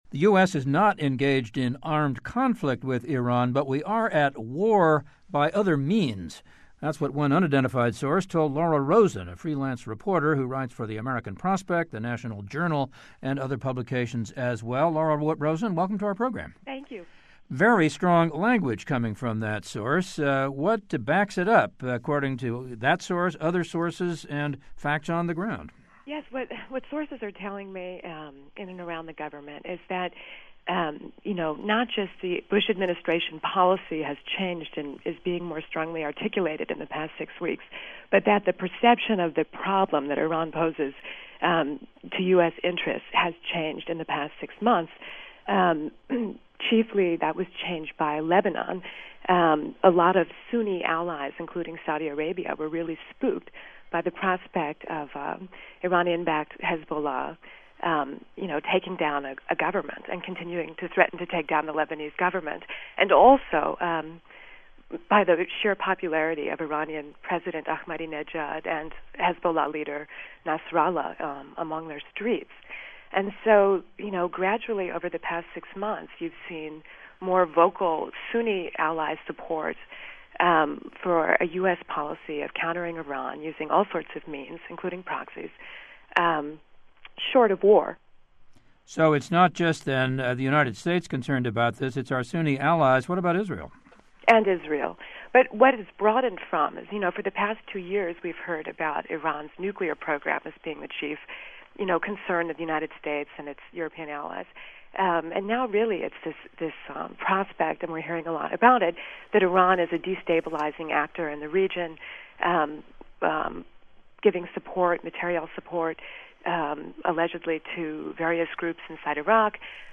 Yet while the United States is not engaged in armed conflict with Iran, sources confirm that we are at "war by other means," and that America’s allies in the Middle East are very supportive. We hear from Middle East experts about rumored US plans for military action.